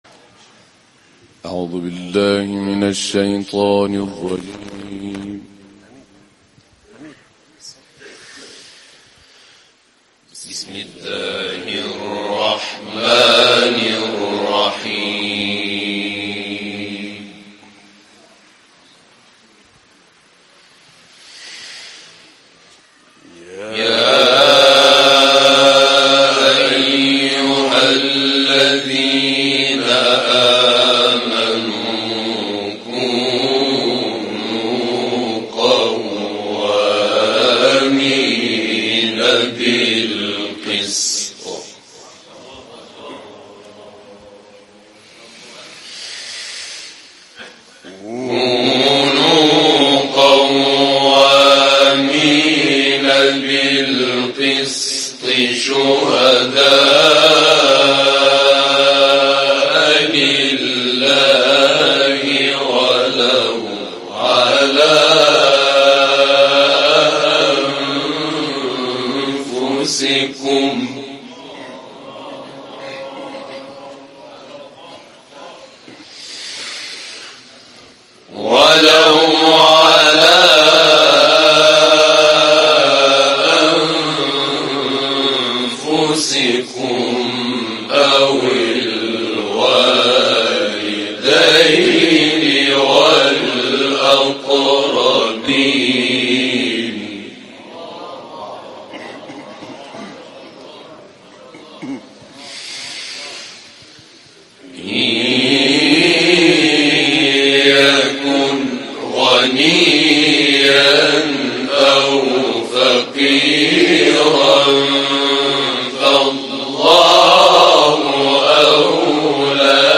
اجرای گروه 35 نفره سادات در محفل دهکده قرآنی چارک